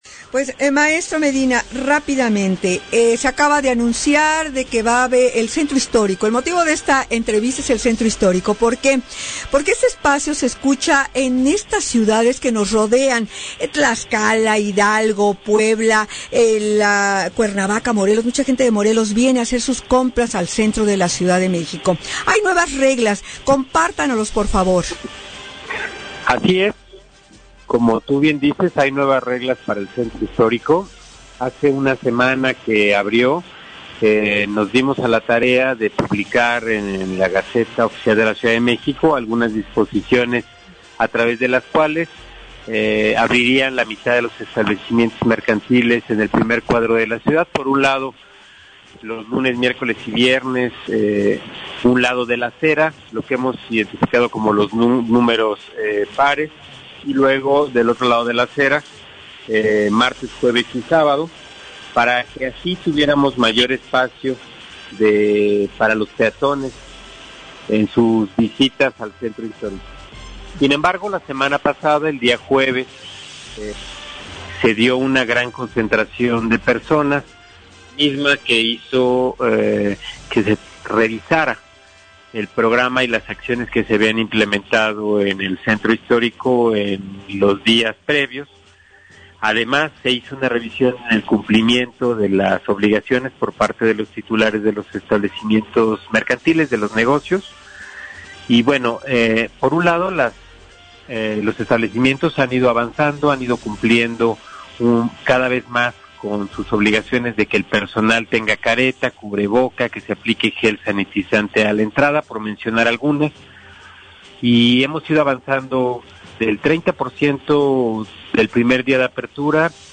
ENTREVISTA CH REAPERTURA 6 JULIO
ENTREVISTA-CH-REAPERTURA-6-JULIO.mp3